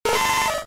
Cri de Nidoran♂ K.O. dans Pokémon Diamant et Perle.